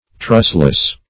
Search Result for " trustless" : The Collaborative International Dictionary of English v.0.48: Trustless \Trust"less\, a. That may not be trusted; not worthy of trust; unfaithful.